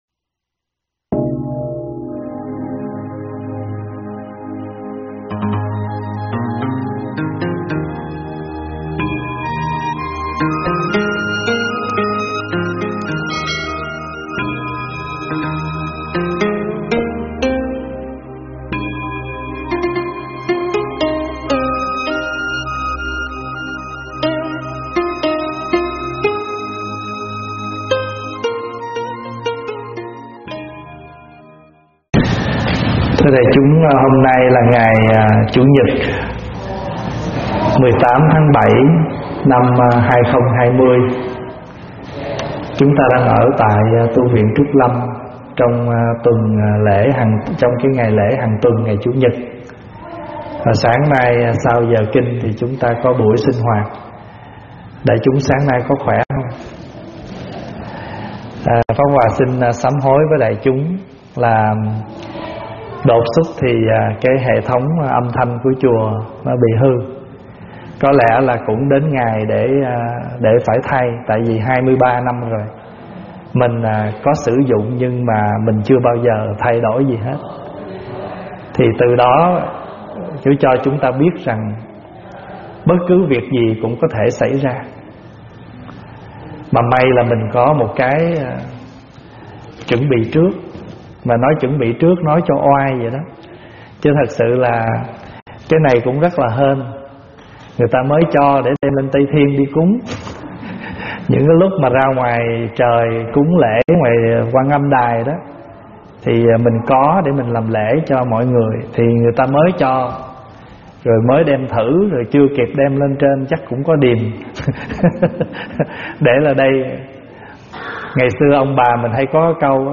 Thuyết pháp Hộ Trì và Tiết Độ